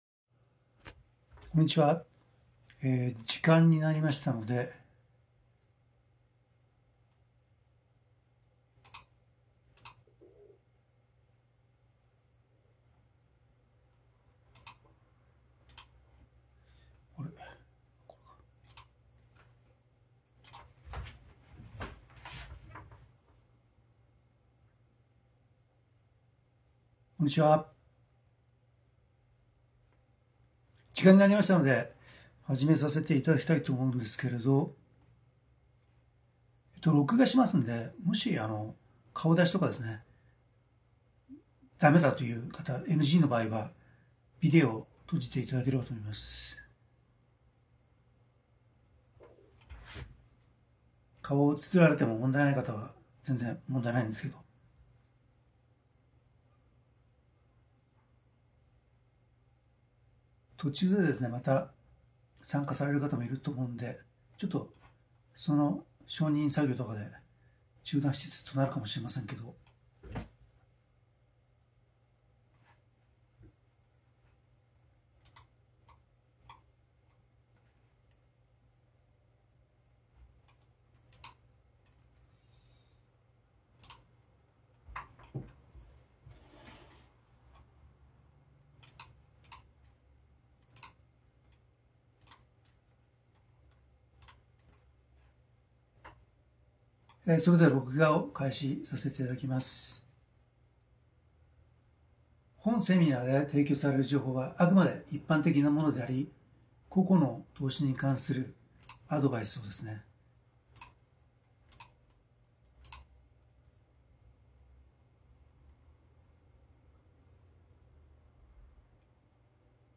音声を少しクリアにした音声だけのバージョンはこちら クリックで再生